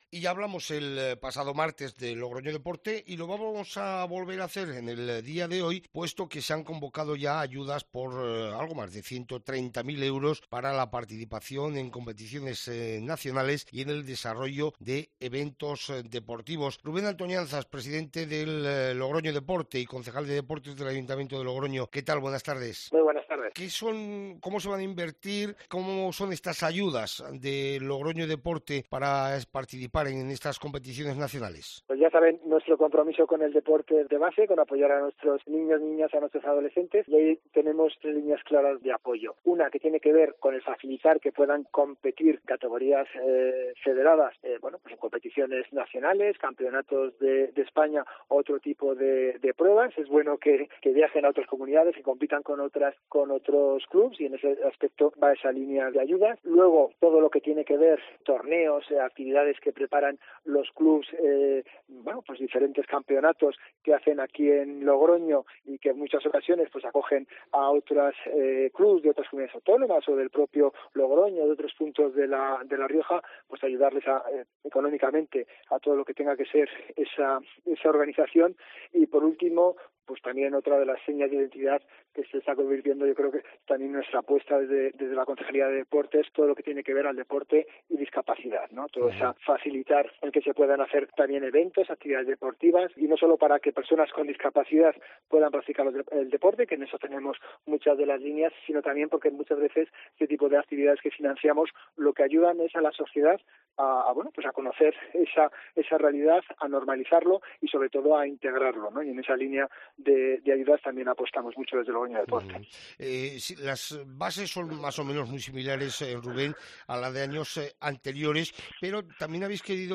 Escucha la entrevista con el concejal de Deportes, Rubén Antoñanzas: